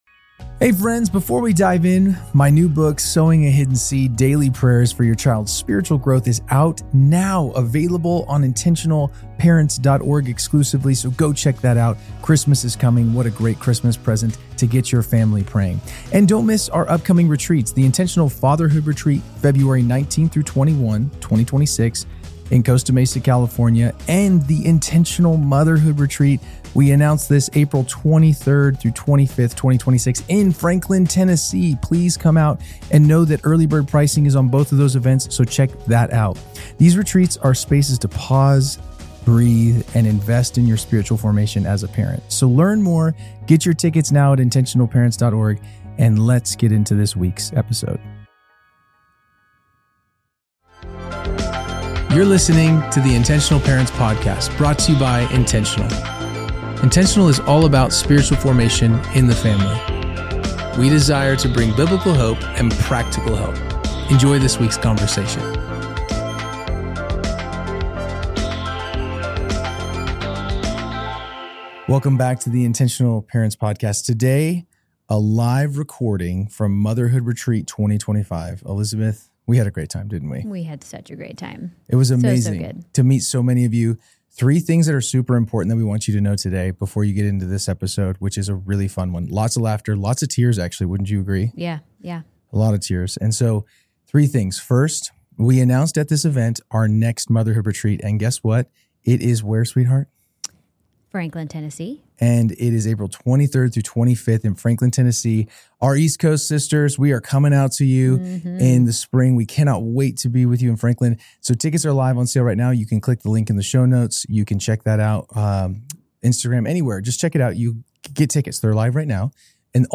Generational Differences with Self-Care, Child Loss, Social Media Addiction, Submitting to Limitations (Live Q+R From Intentional Motherhood Retreat 2025)